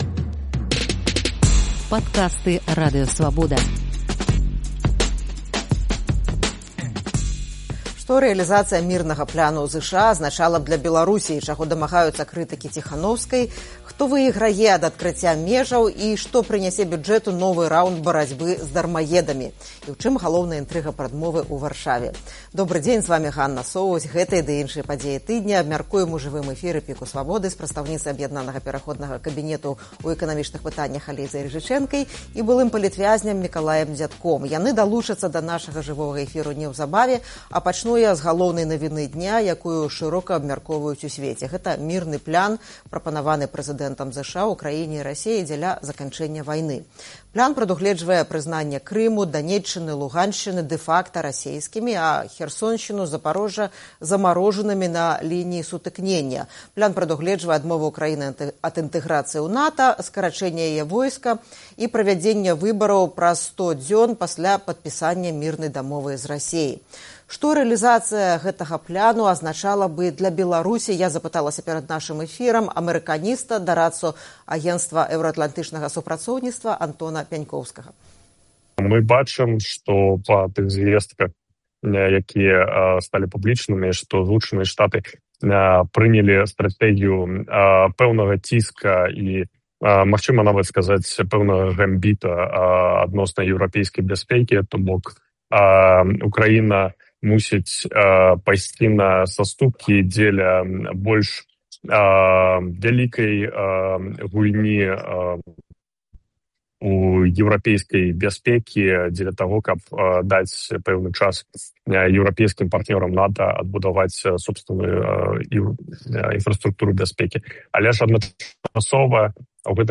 Гэтыя ды іншыя падзеі тыдня абмяркоўваем у жывым эфіры «ПіКу Свабоды»